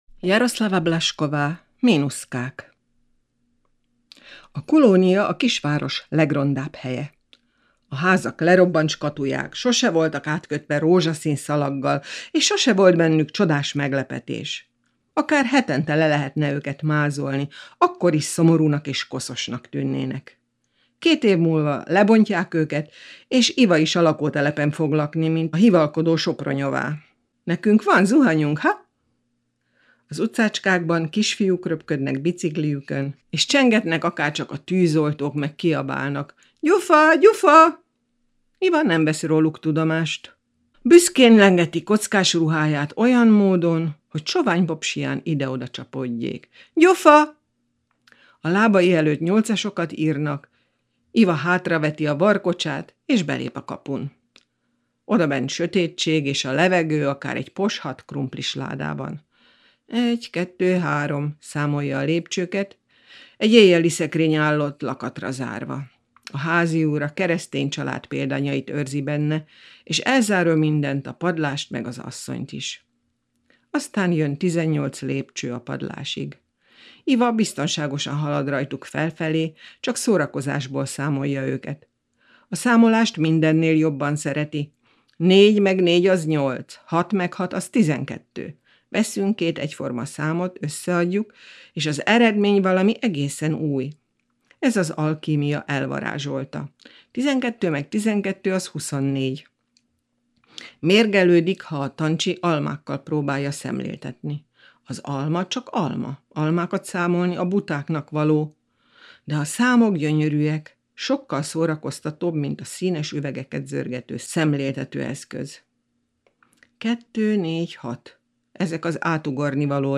Jaroslava Blažková: Mínuszkák (audionovella, részlet A fehér harisnya című kötet egyik novellájából)